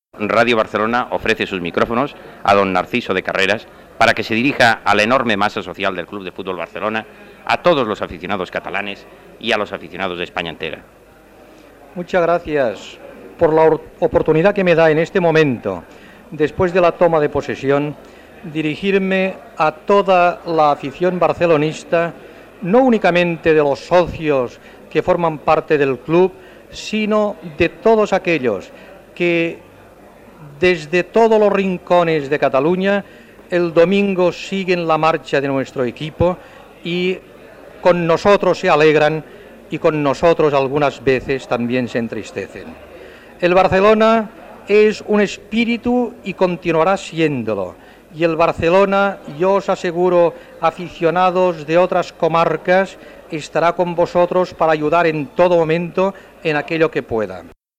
Paraules de Narcís de Carreras una vegada que ha pres possessió del càrrec de president del Futbol Club Barcelona
Esportiu